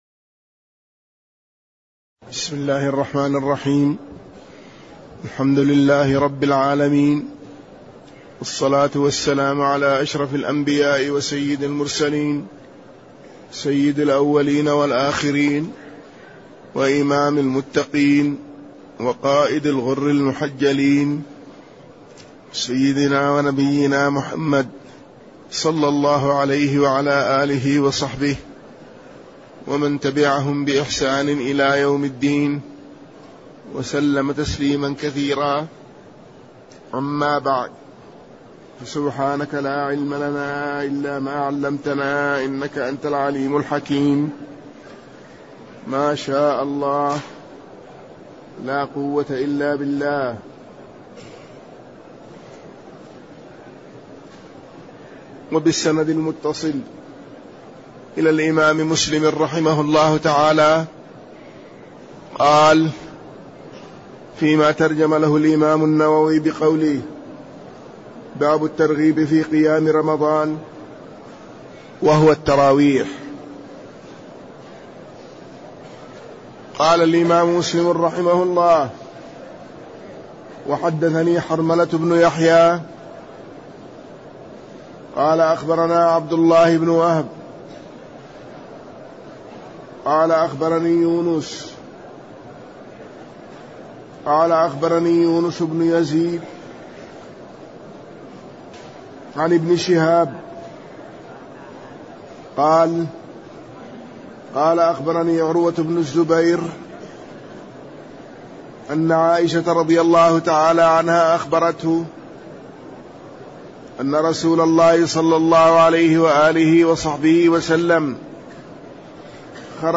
تاريخ النشر ٢٩ رمضان ١٤٣٠ هـ المكان: المسجد النبوي الشيخ